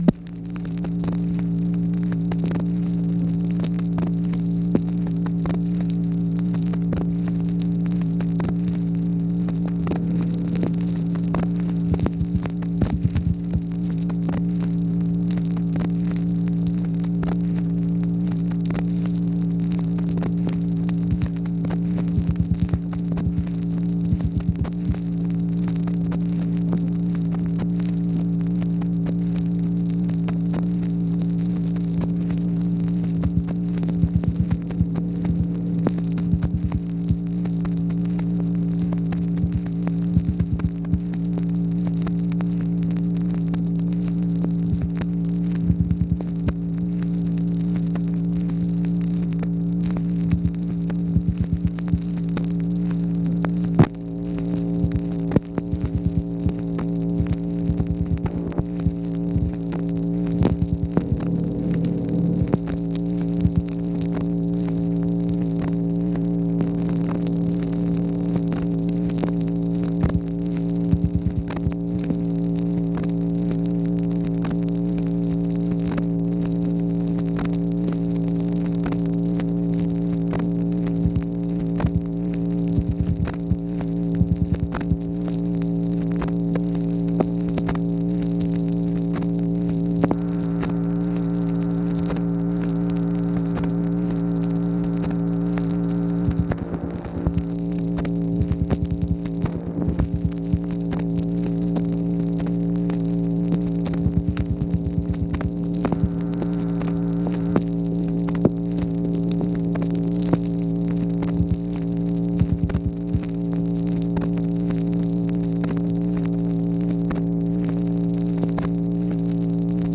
MACHINE NOISE, June 30, 1964
Secret White House Tapes | Lyndon B. Johnson Presidency